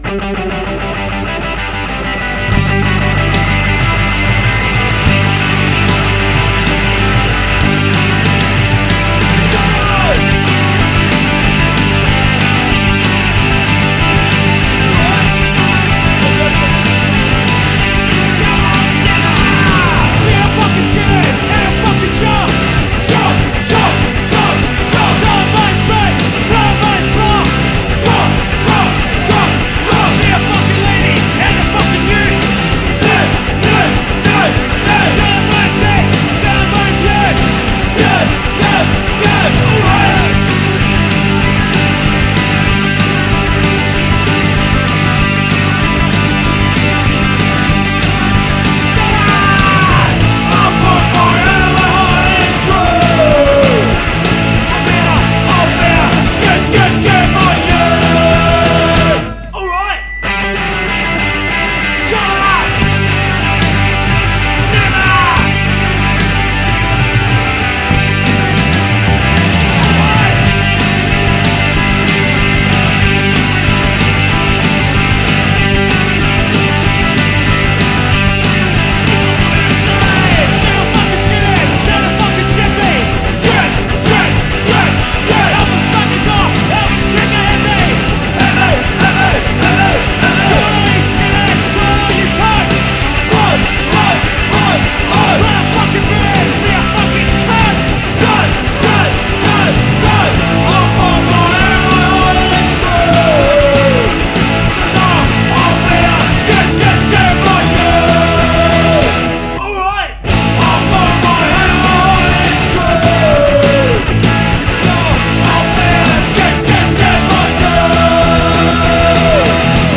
Firmly mid-tempo, with giant guitar leads